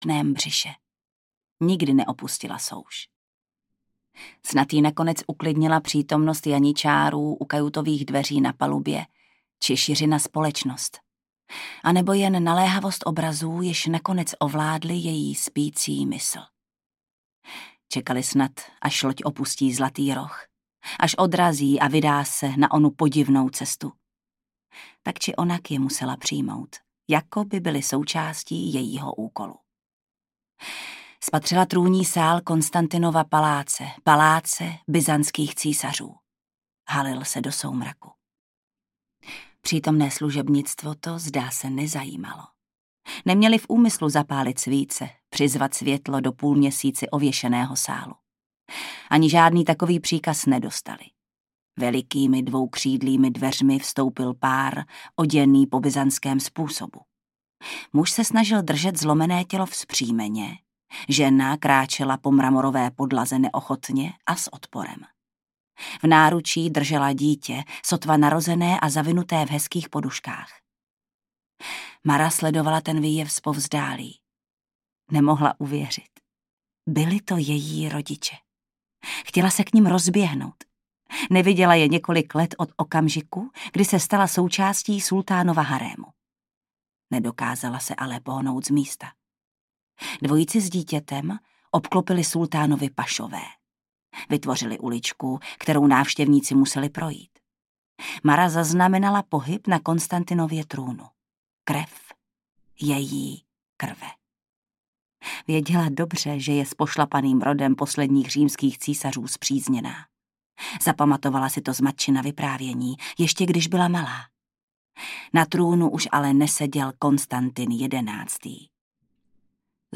Syn draka audiokniha
Ukázka z knihy
syn-draka-audiokniha